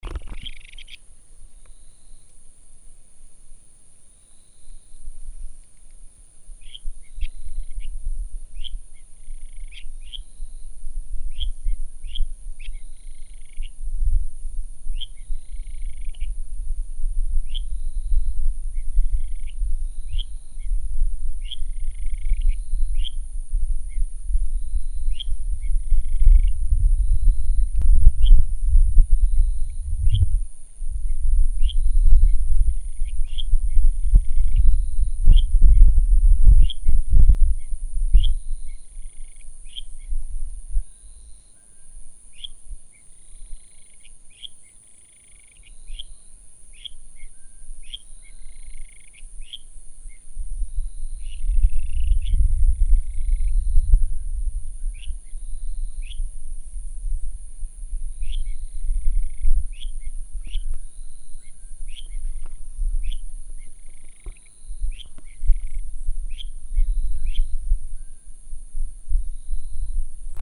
日本樹蛙 Buergeria japonica
花蓮縣 壽豐鄉 池南森林遊樂區
錄音環境 次生林旁草叢
2隻競叫
錄音: 廠牌 Samson 型號 Zoom H4